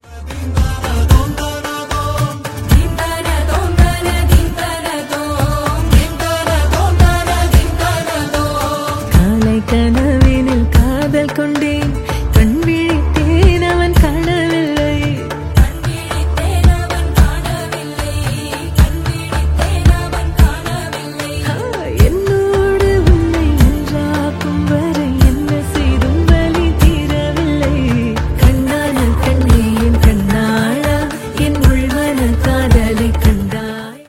Ringtone File